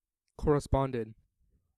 Ääntäminen
Ääntäminen US Haettu sana löytyi näillä lähdekielillä: englanti Käännöksiä ei löytynyt valitulle kohdekielelle. Corresponded on sanan correspond partisiipin perfekti.